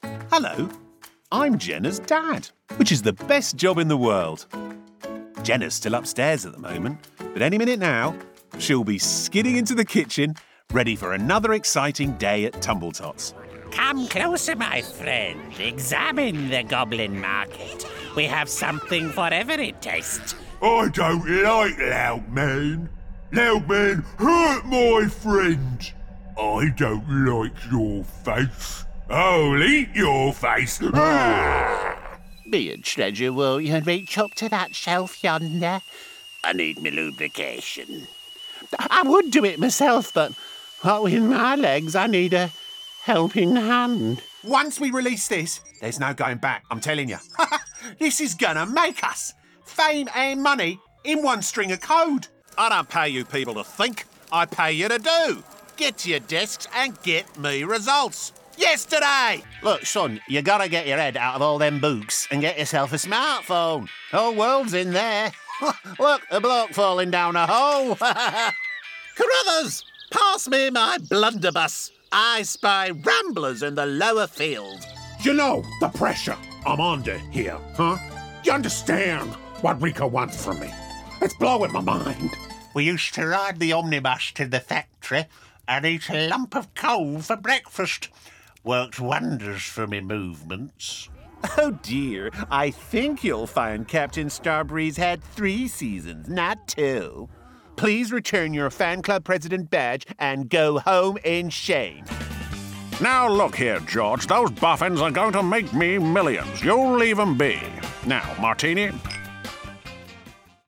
Animation Showreel
Male
Neutral British
Playful
Quirky
Upbeat